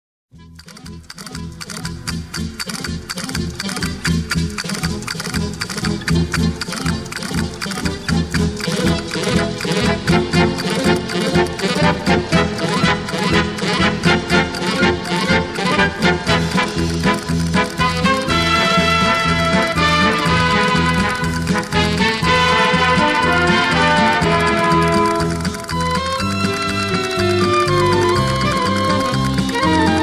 Dance: Paso Doble 60